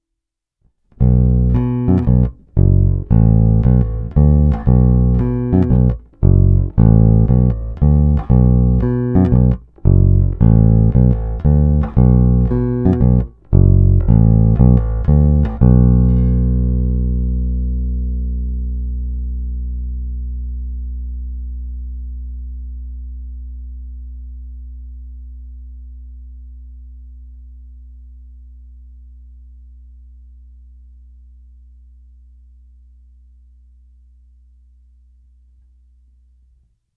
Není-li uvedeno jinak, jsou ukázky nahrány rovnou do zvukové karty a jen normalizovány. Hráno vždy nad aktivním snímačem, v případě obou pak mezi nimi.
Snímač u krku